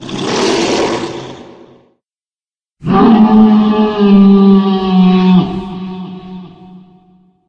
Divergent / mods / Soundscape Overhaul / gamedata / sounds / monsters / lurker / hit_3.ogg